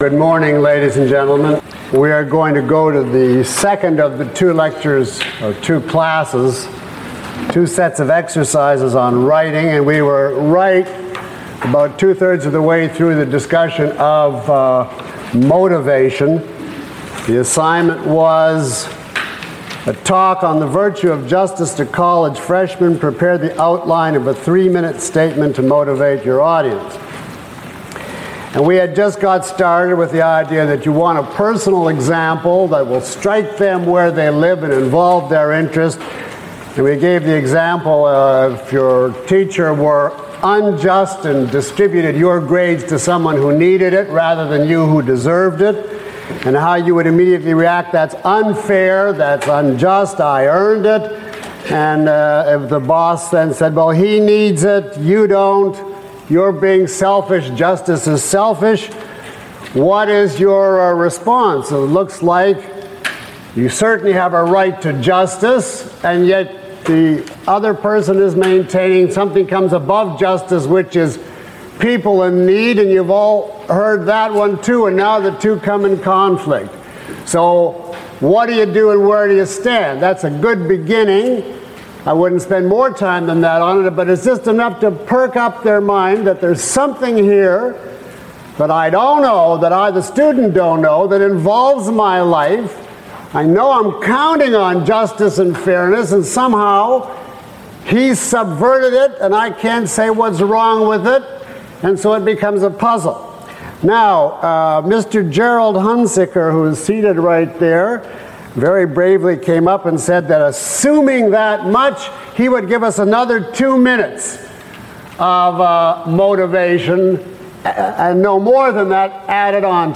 Course summary: In this two-lecture mini-course, Dr. Leonard Peikoff discusses six essential elements of good writing—selectivity, structure, tone, context, motivation, and condensation. Using exercises provided for the audience, he conducts detailed analysis of writing with extensive interactive questions and answers from the audience.